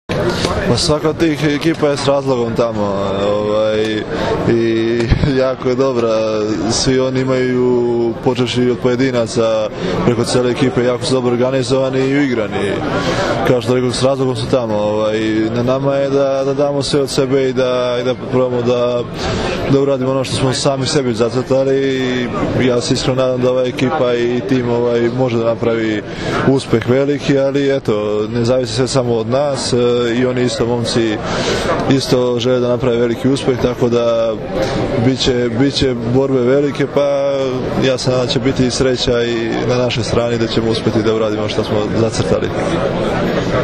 IZJAVA MARKA IVOVIĆA